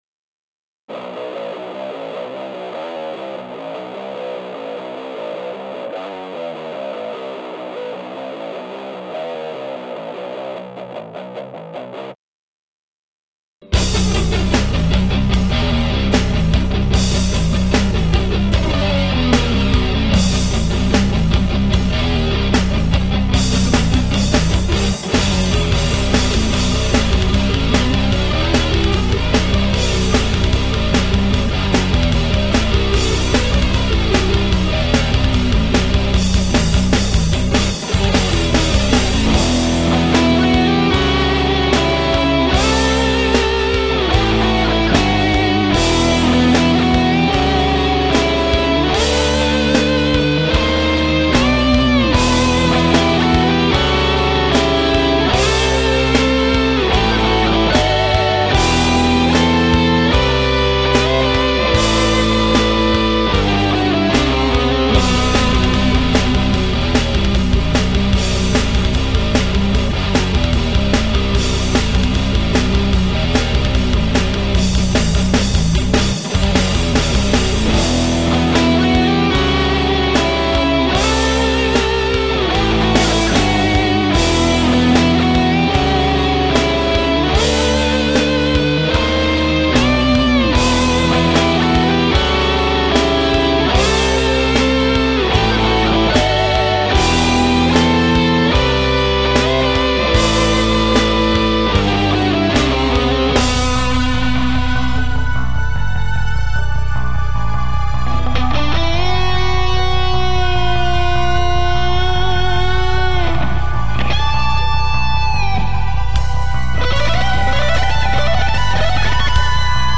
metal2.mp3